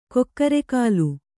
♪ kokkare kālu